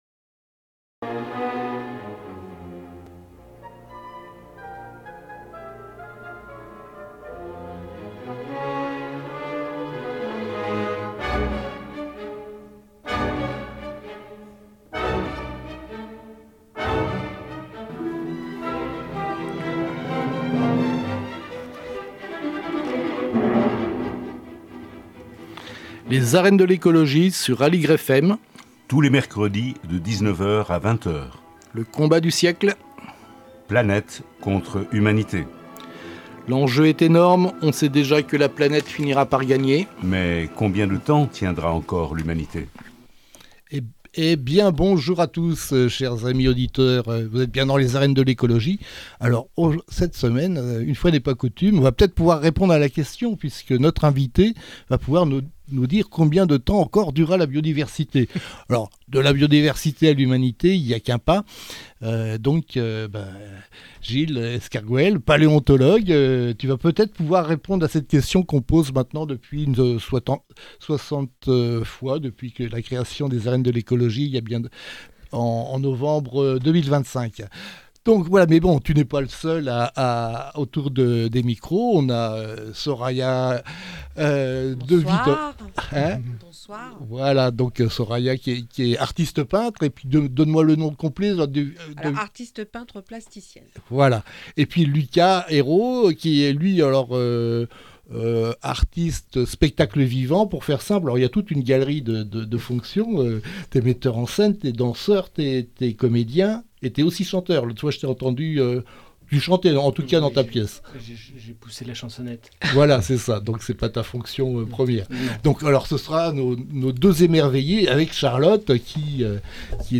Le scientifique nous contera la 4è extinction qui décima 85 % des espèces vivantes apparentes. Il nous dira ce que les fossiles nous enseignent de notre anthropocène actuel, depuis que les sociétés humaines accélèrent la destruction de la biodiversité à un rythme incomparablement plus rapide que les phénomènes observés dans les couches géologiques.